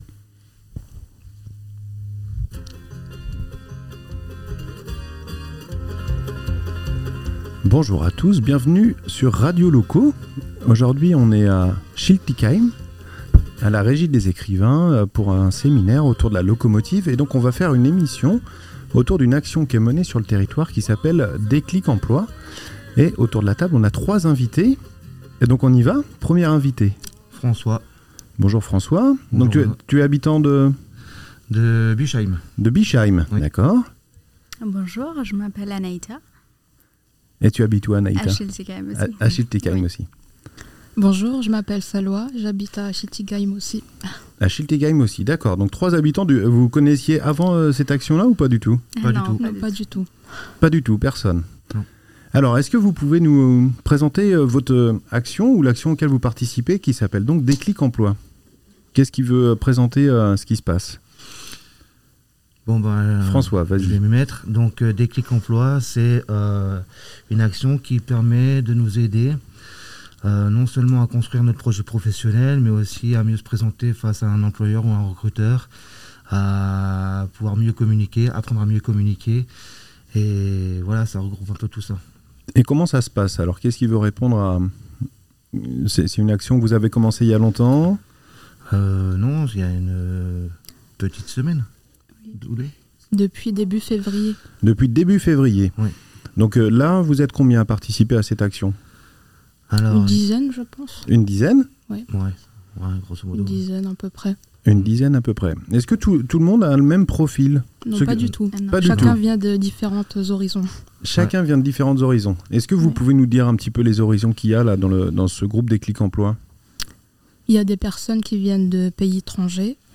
Radio Loco ! Un Podcast enregistré en mars 2024 avec des habitants Alsaciens participants à l'action "D CLiq Loco" dans le cadre du programme La Locomotive. L'émission a été enregistrée à la Régie des Ecrivains .